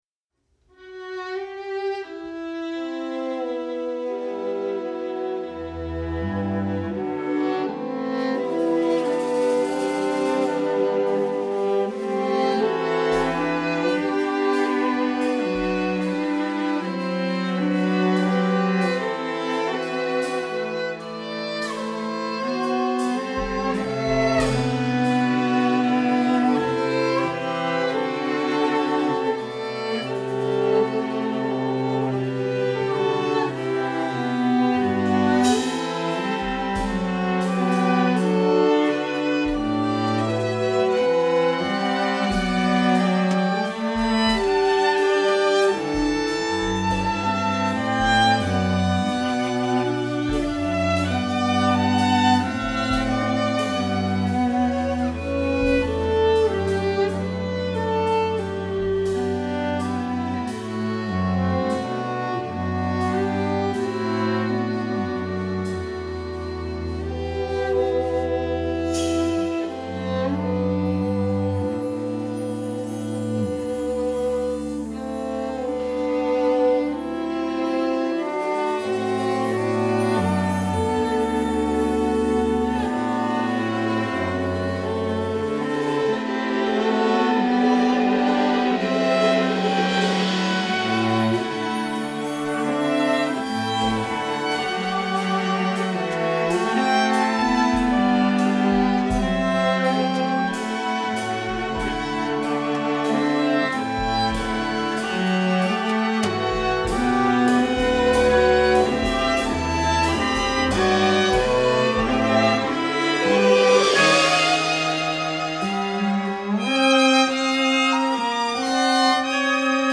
perkusistę